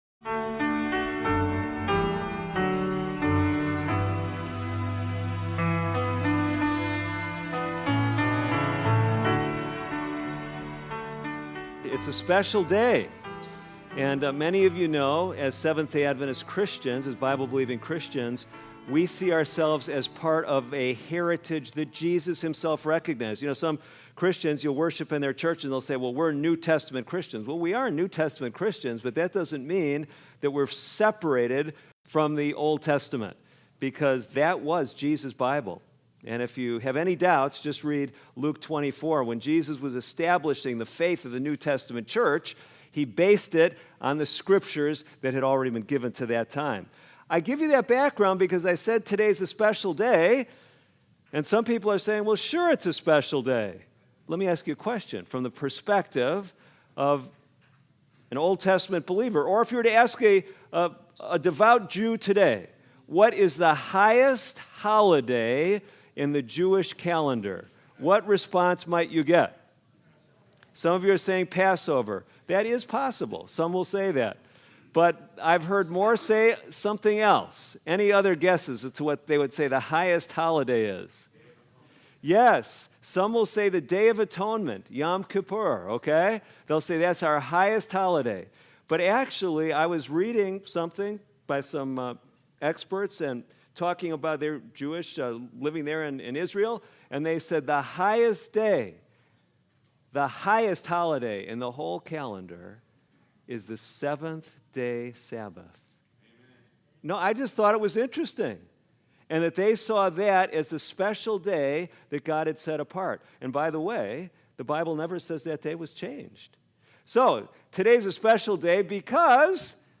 Through the story of Mephibosheth, this sermon reveals how God’s covenant grace reaches the unworthy, restores the broken, and redefines our identity in Christ.